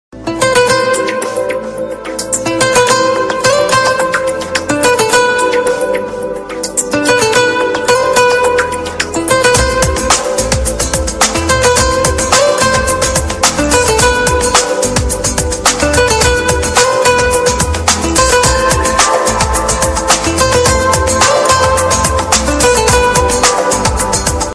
Category : Guitar